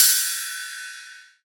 • Trap Ride One Shot F Key 05.wav
Royality free ride cymbal audio clip tuned to the F note.
trap-ride-one-shot-f-key-05-IFE.wav